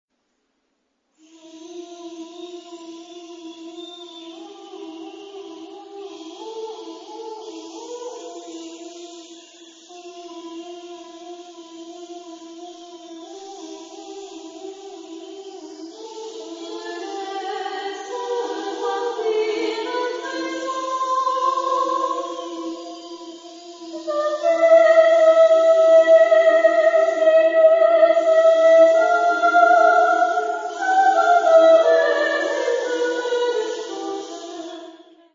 Género/Estilo/Forma: Profano ; Poema ; contemporáneo
Solistas : Soprano (1)  (1 solista(s) )
Tonalidad : polimodal